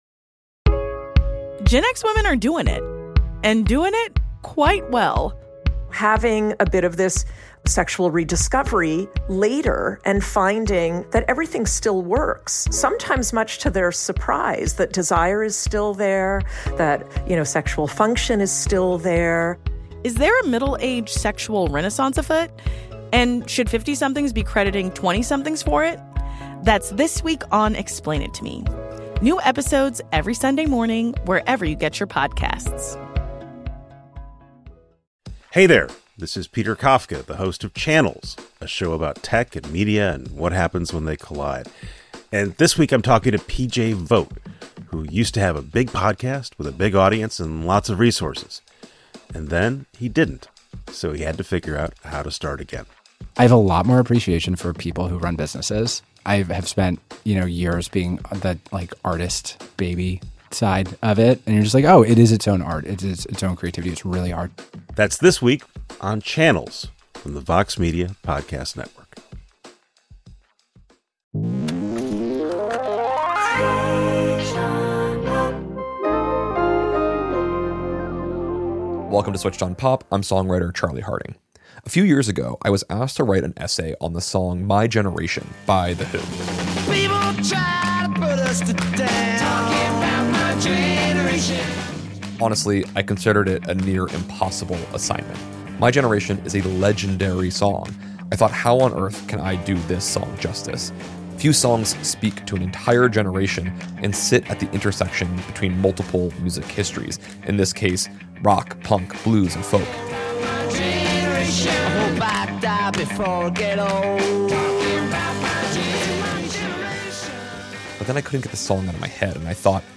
In a rare interview, Pete Townshend reveals how six fans at London's Goldhawk Club in 1965 directly asked him to write an anthem for their post-war generation. This conversation uncovers how a simple request transformed into rock's definitive youth statement, complete with its rebellious stutter and blues foundations.